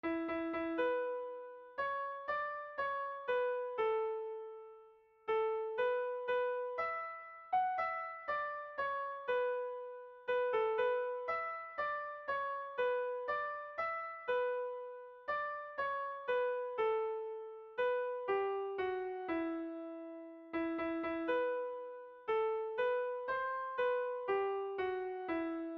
en valse à cinq temps